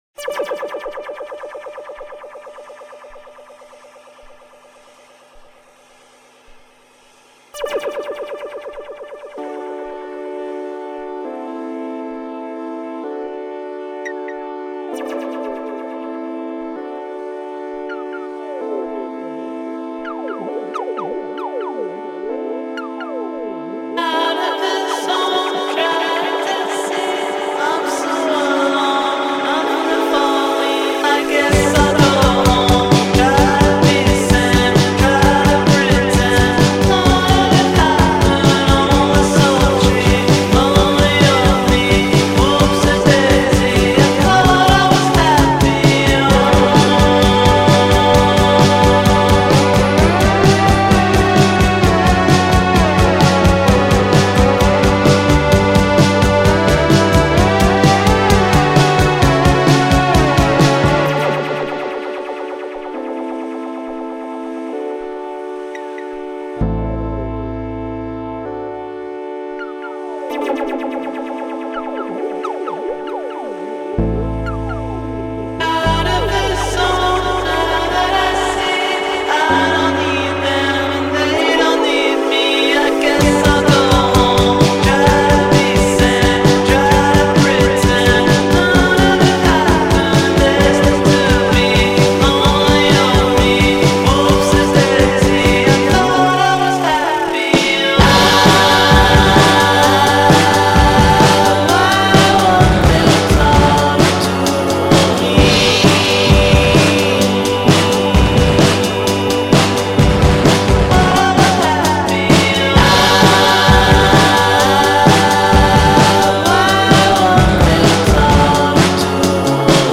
Un viaggio psichedelico.